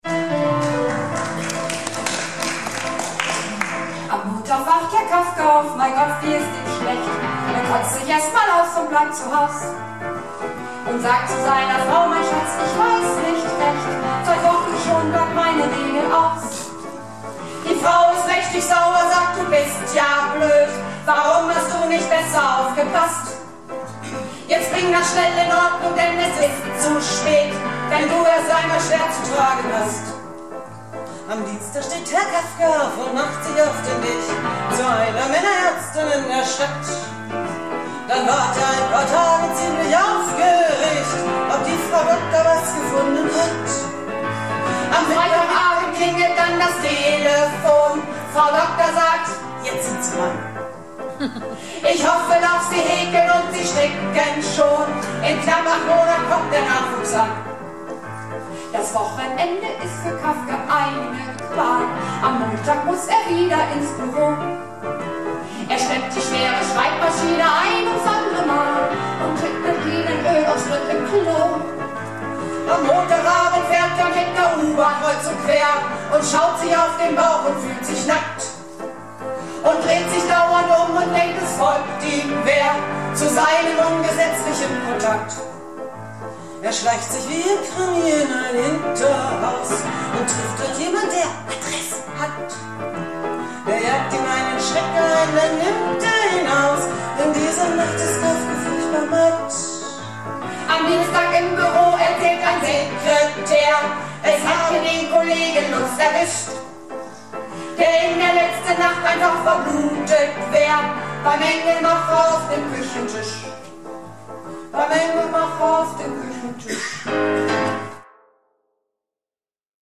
Theater - "Du musst es wagen - Sünner Tegenstöten word nümms deftig" am 18.03.2012 in Emden